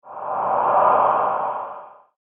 Ghostly-ahh-transition-sound-effect.mp3